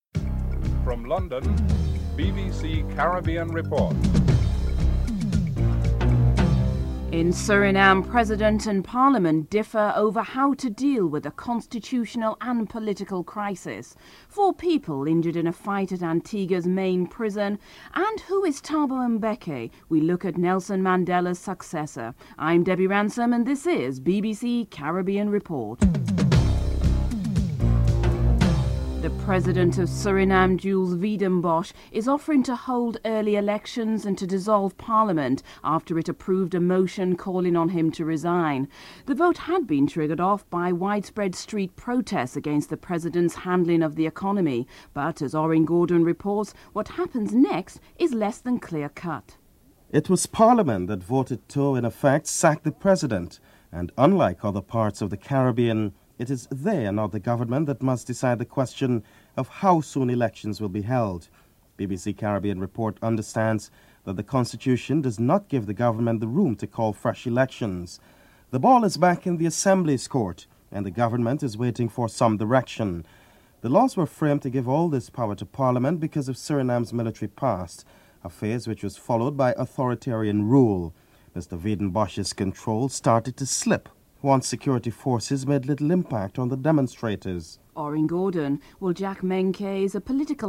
1. Headlines with anchor